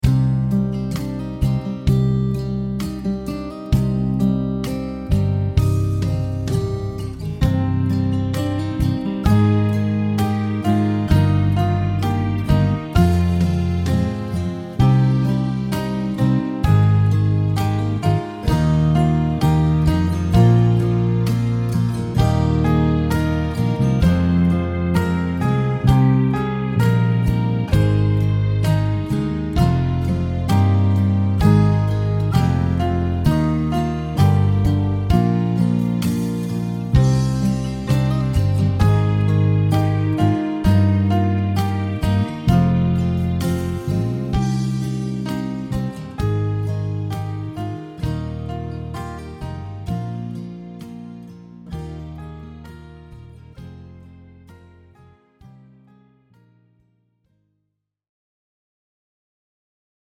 Latviešu tautas dziesma Play-along.
Spied šeit, lai paklausītos Demo ar melodiju